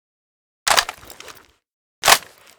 aug_reload.ogg